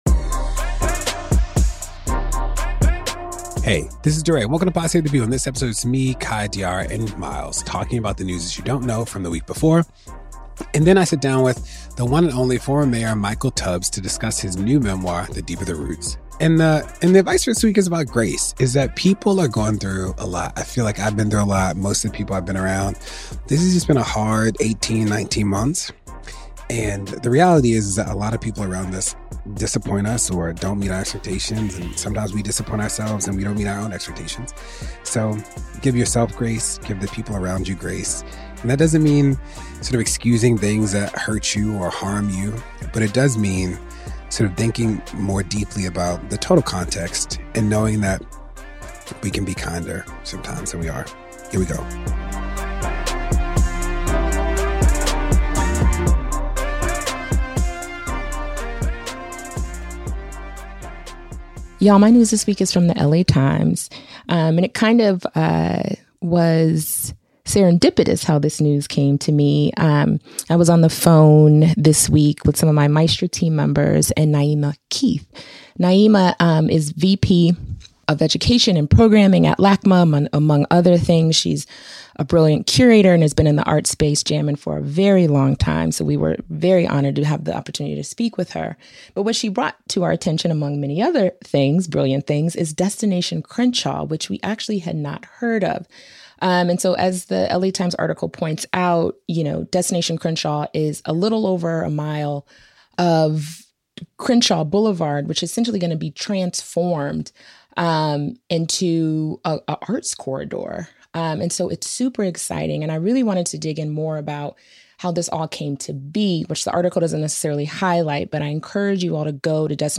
Then, DeRay interviews Michael Tubbs, author of "The Deeper the Roots."